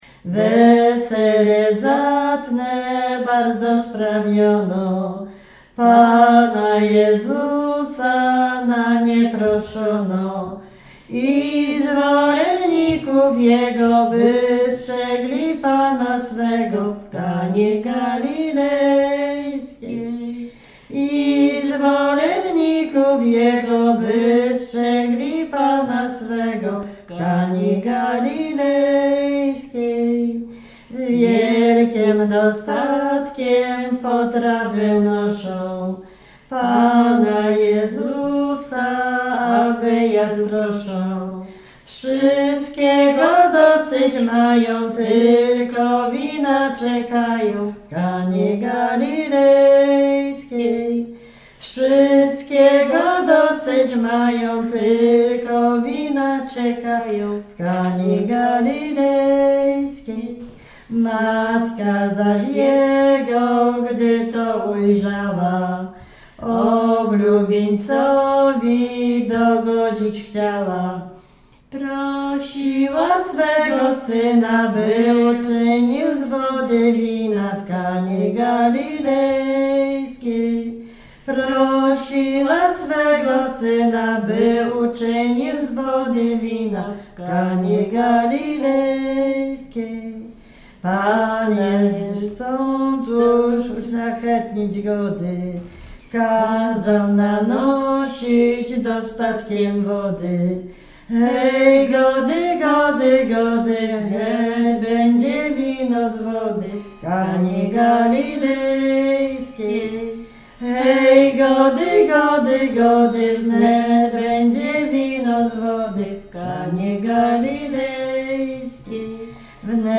Śpiewaczki z Nowej Wsi Lubińskiej
Dolny Śląsk, powiat polkowicki, gmina Polkowice, wieś Nowa Wieś Lubińska
Śpiewaczki przesiedlone po 1945 r ze wsi Tuligłowy na Dolny Śląsk
Kolęda